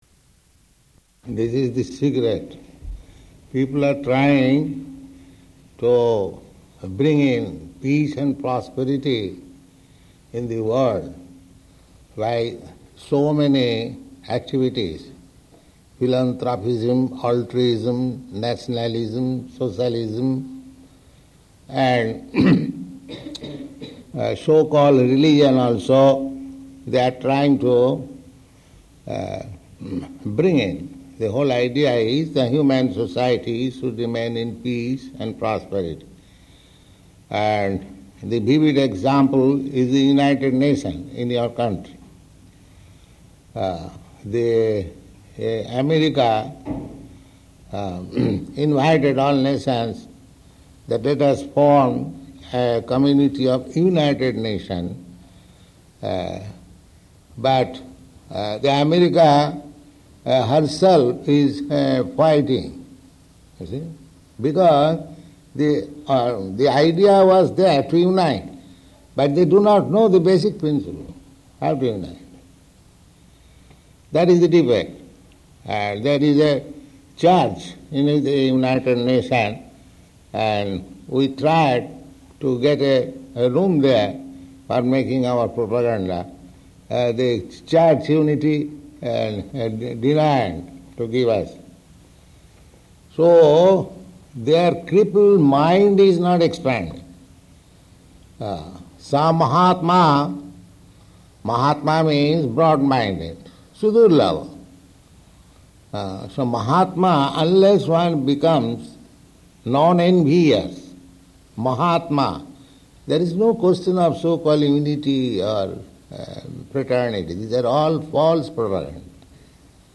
Lecture [partially recorded]
Location: Los Angeles